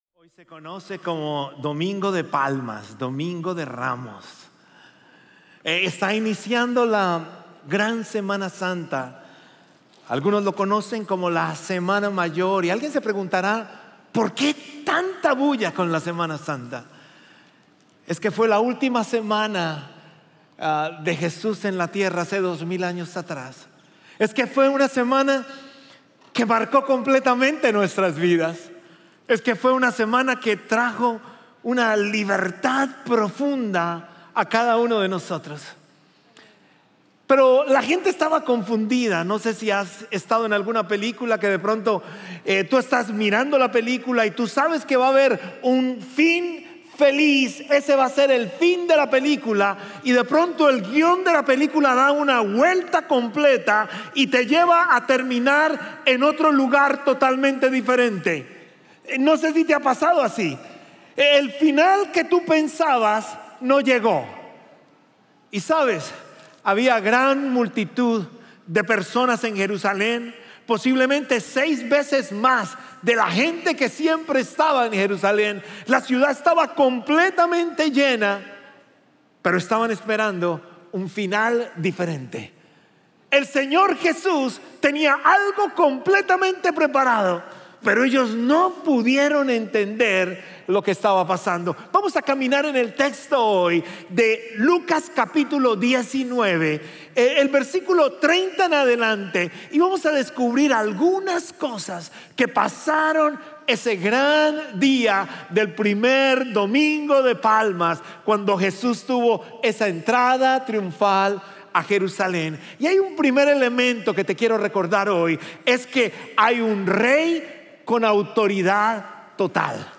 Sermones Conroe – Media Player